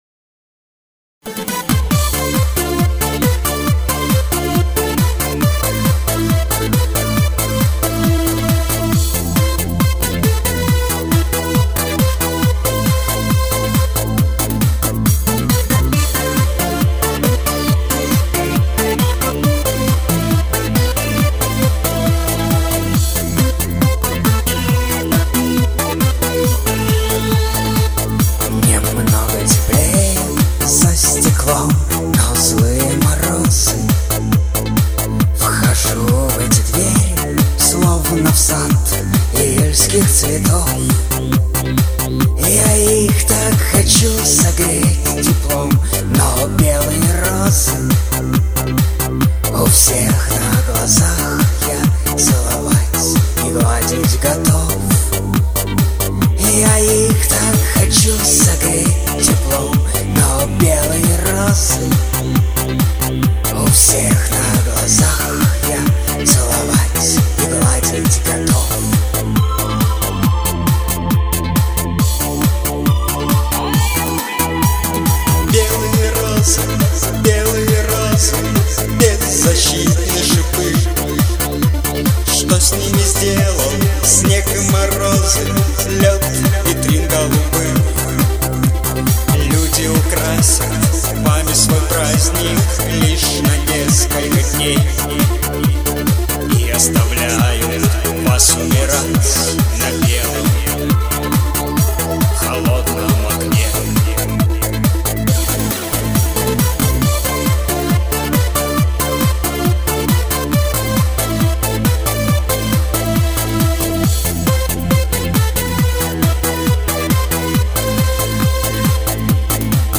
не понимаю этой хрипотцы и приглушенности)))))